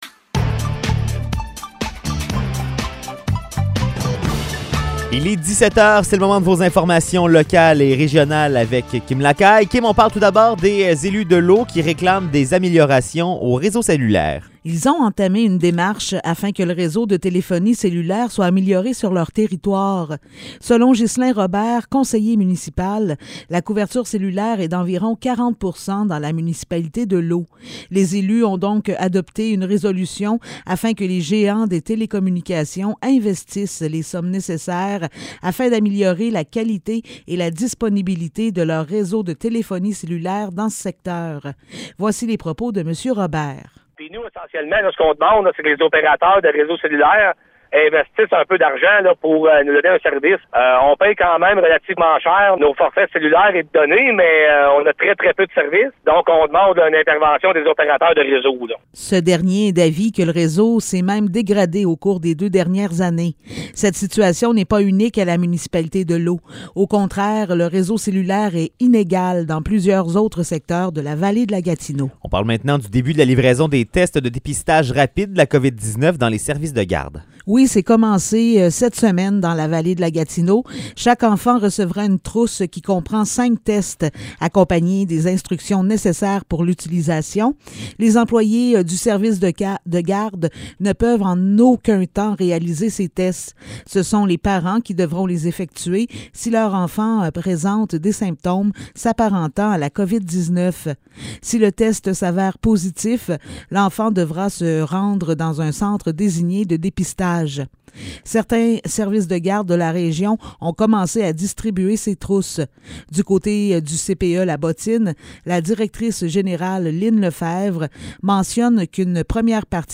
Nouvelles locales - 15 décembre 2021 - 17 h